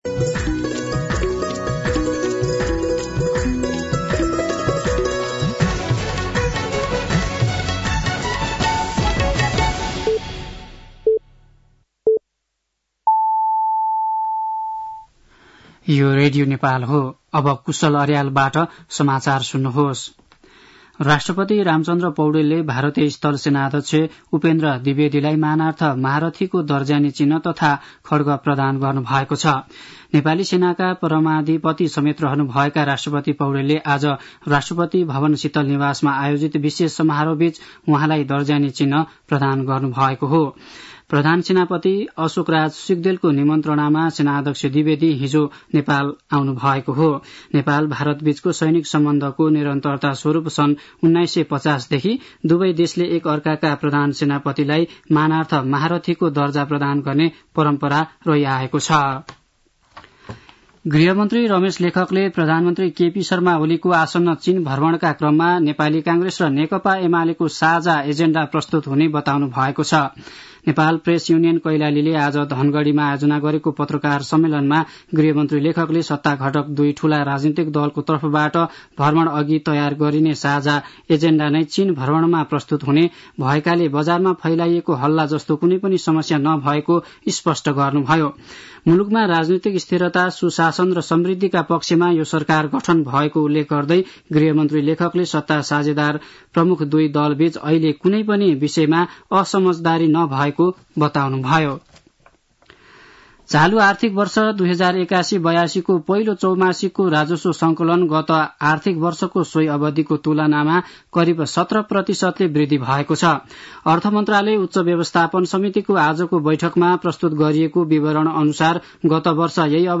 साँझ ५ बजेको नेपाली समाचार : ७ मंसिर , २०८१
5pm-news-8-6.mp3